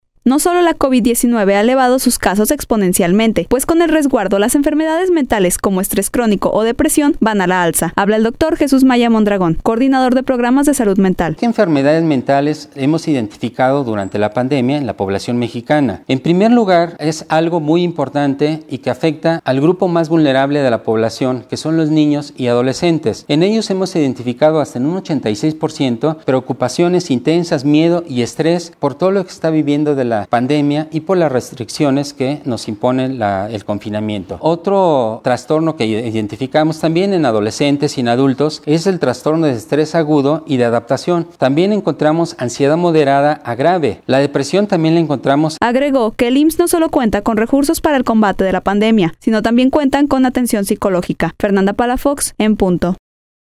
No sólo la COVID 19 ha elevado sus casos exponencialmente, pues con el  resguardo las enfermedades mentales, como estrés crónico o depresión van a la alza. Habla el doctor